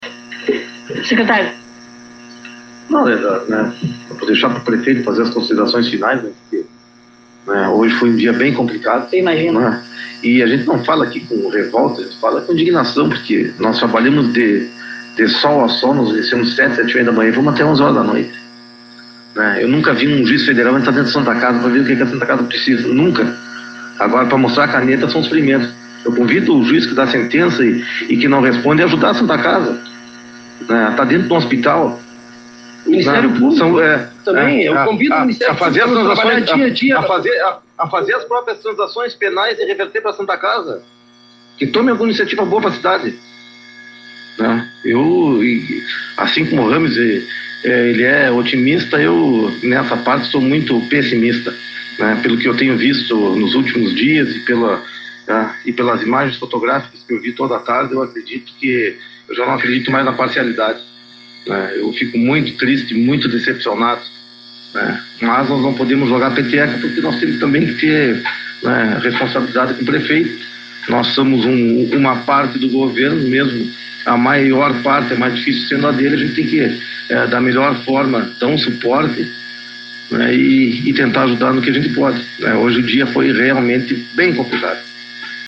O secretário Geral de Governo, Enrique Civeira, desafiou o Juiz Lademiro Dors Filho, responsável pela 1ª Vara Federal de Sant’Ana do Livramento, a entrar na Santa Casa de Misericórdia do Município. A afirmação foi feita em uma entrevista a uma rádio local, na tarde de sexta-feira (27), logo após divulgada a sentença do magistrado que condenou o prefeito Ico Charopen (PDT) à suspensão dos seus direitos políticos por três anos.
ENTREVISTA.mp3